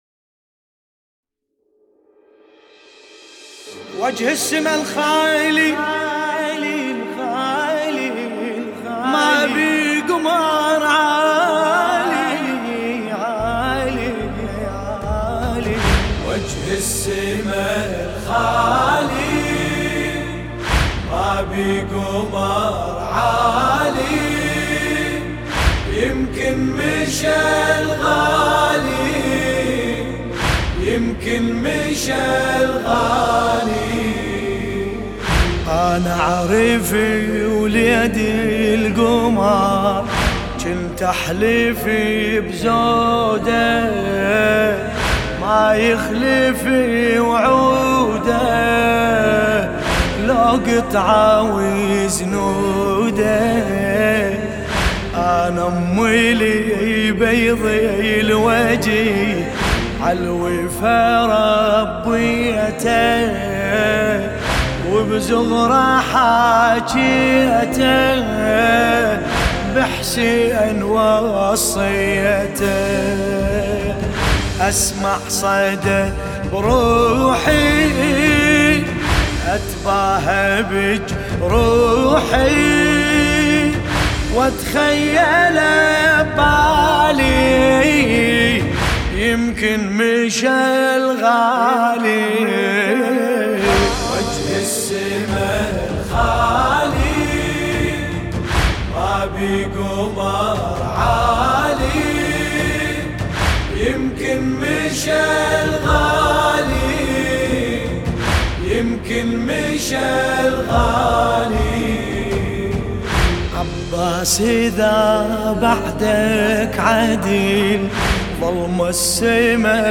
سینه زنی